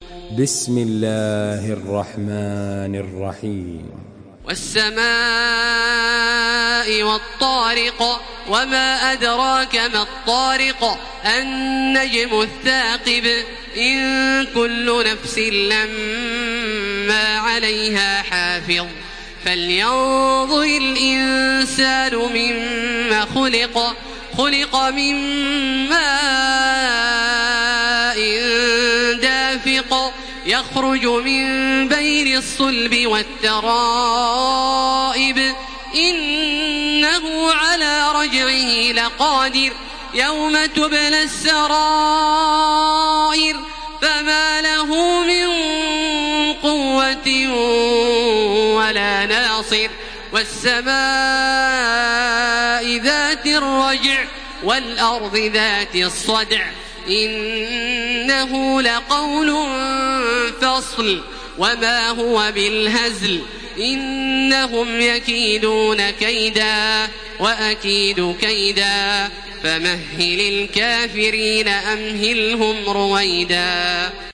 Surah At-Tariq MP3 in the Voice of Makkah Taraweeh 1433 in Hafs Narration
Murattal